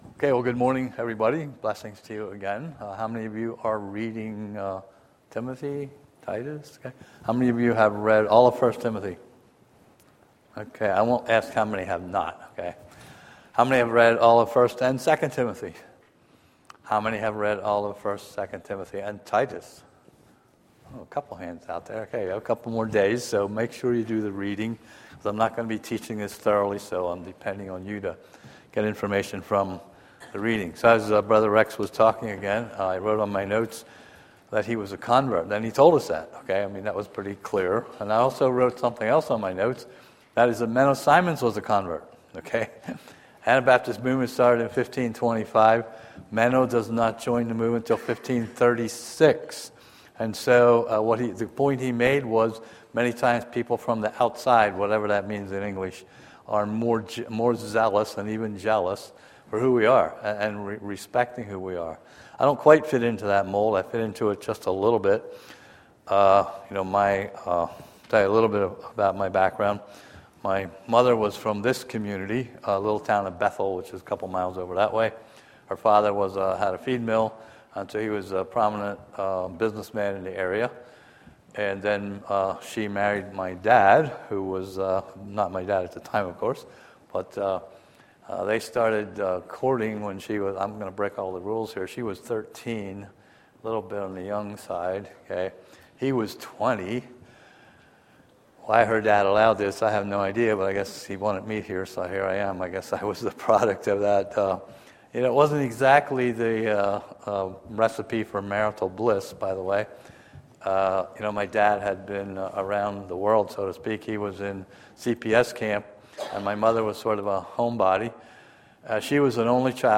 Bible Study - Harmony Christian Fellowship